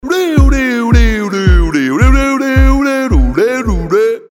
смешные
голосовые
Забавный звук на сообщения и уведомления